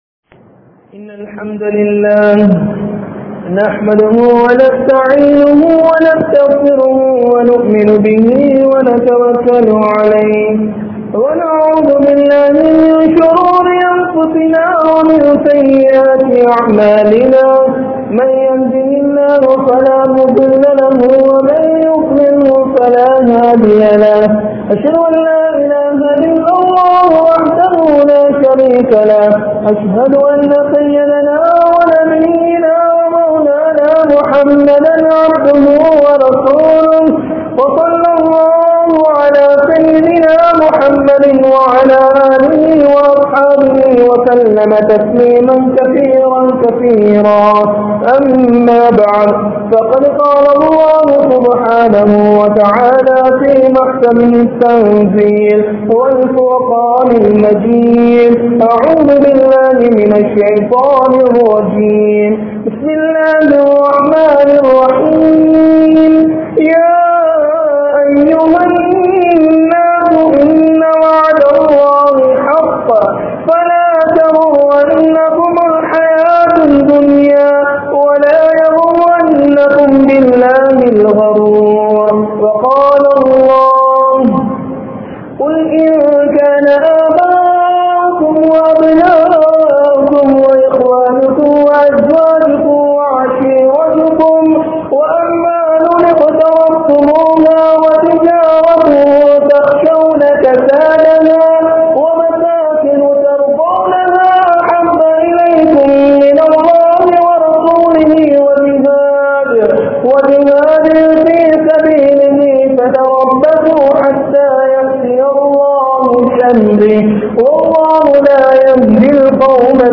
Manithanai Eamaattrum Ulaham (மனிதனை ஏமாற்றும் உலகம்) | Audio Bayans | All Ceylon Muslim Youth Community | Addalaichenai
Majma Ul Khairah Jumua Masjith (Nimal Road)